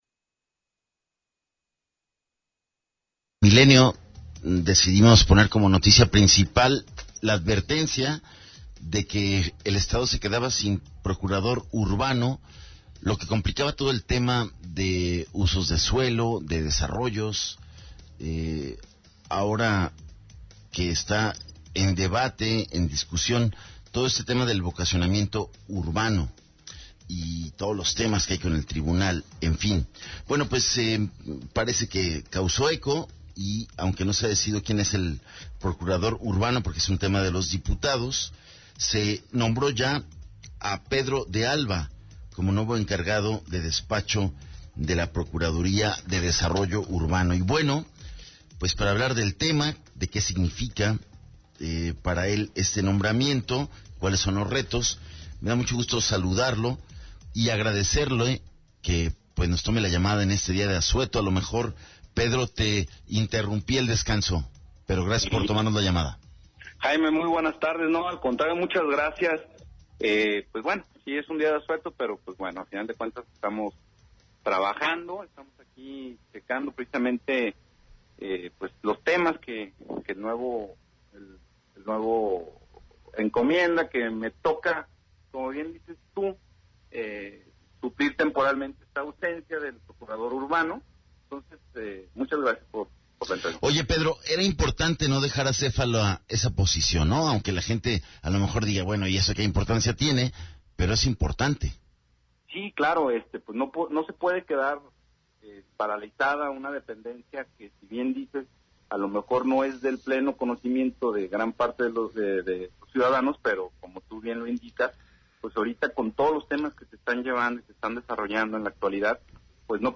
ENTREVISTA 160915